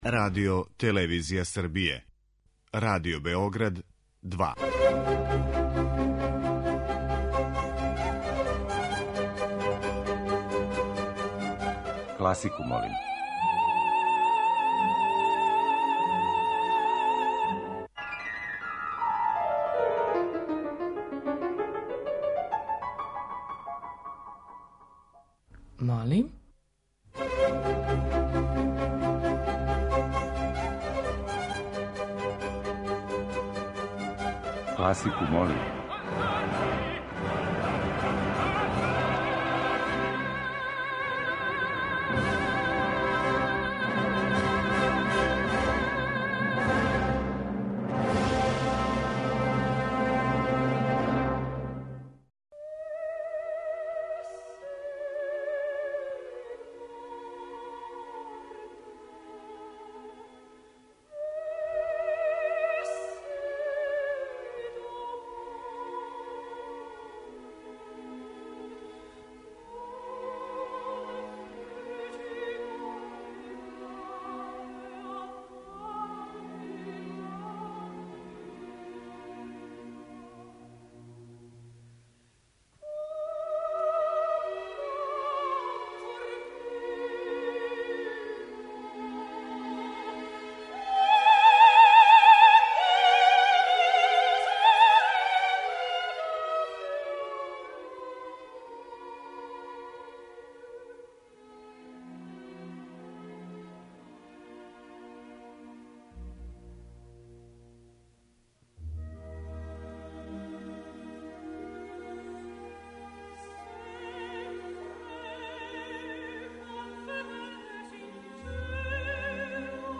Тема циклуса биће арије из репертоара Марије Калас, које је Марина Абрамовић употребила за свој оперски перформанс премијерно изведен 1. септембра у Баварској државној опери у Минхену.